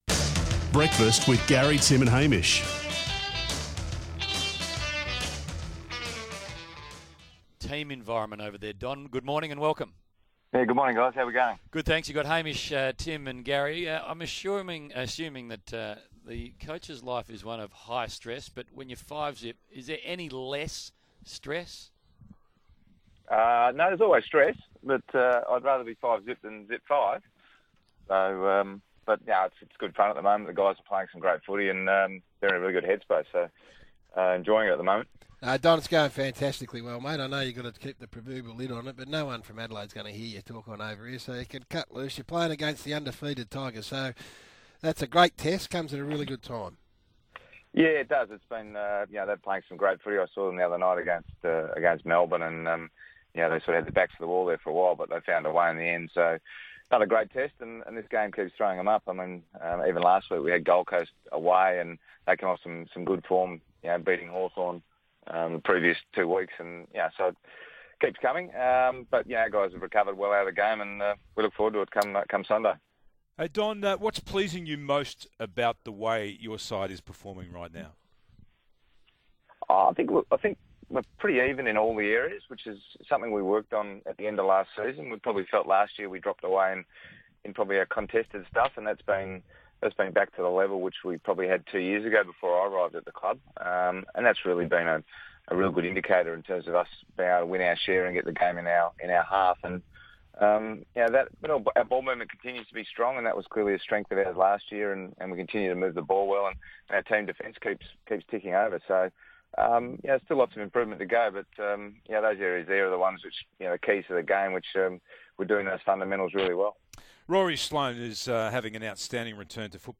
Don Pyke chats with Garry Lyon, Tim Watson and Hamish McLachlan